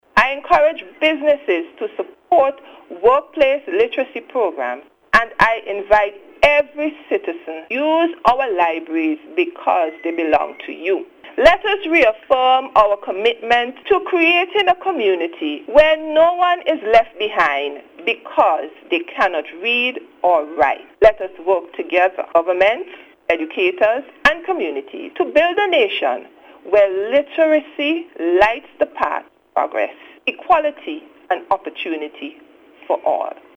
In a passionate appeal during a recent interview, Campbell called on government officials, educators, and community leaders to work collectively in building a literate society, where no one is left behind.